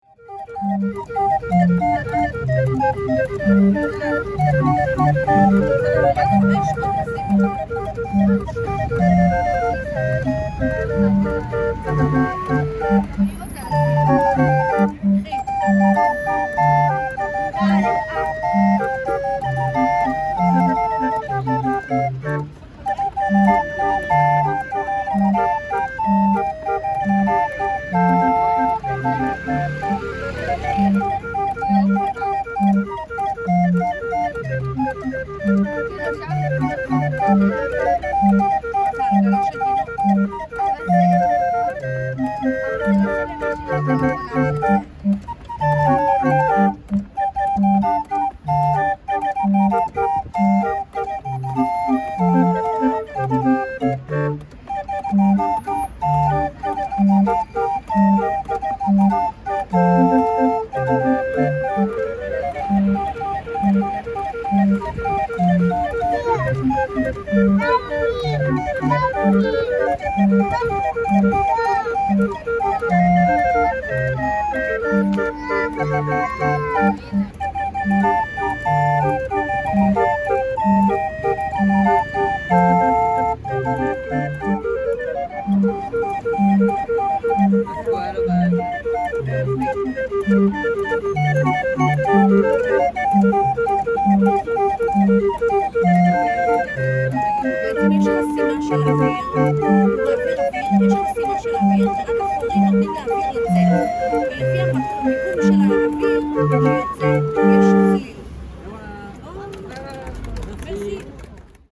Звук игры на древней шарманке